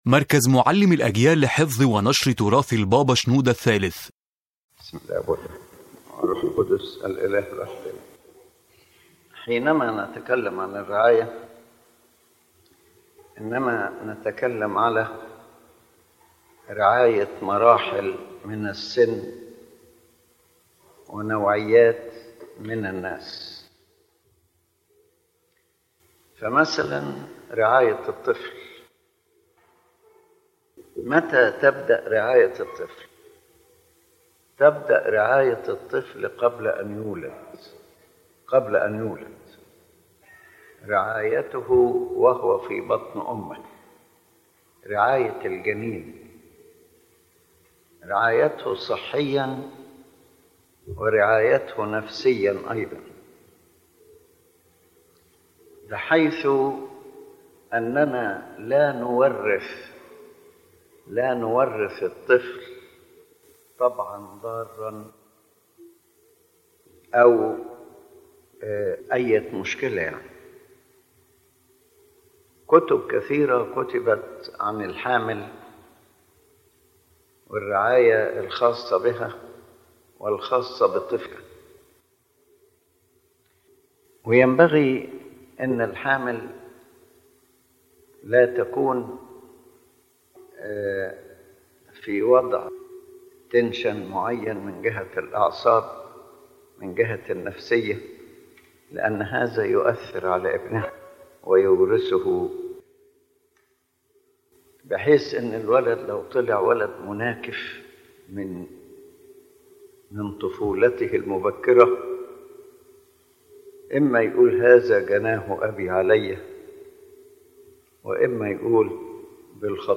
تؤكد المحاضرة لقداسة البابا شنوده الثالث أن رعاية الطفل تبدأ قبل ولادته، وتمتد عبر كل مراحل نموه، وأنها مسؤولية مشتركة بين الأسرة والكنيسة والمجتمع.